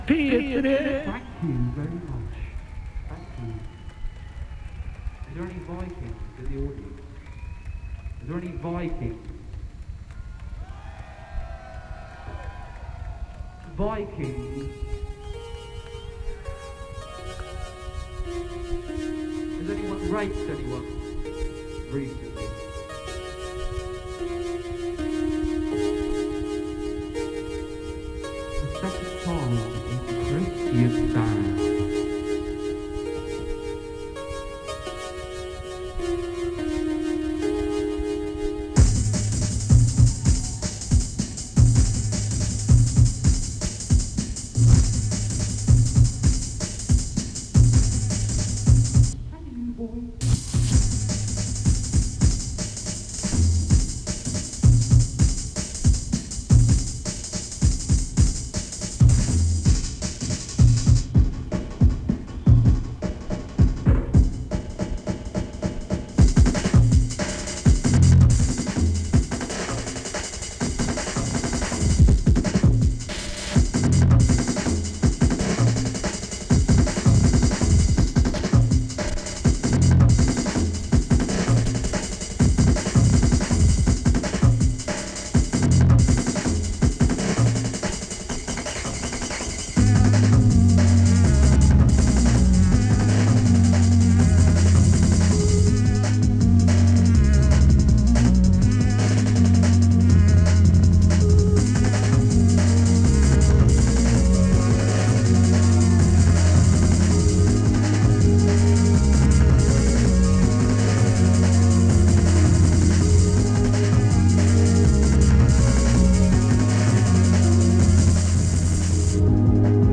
location Norway, Kristiansand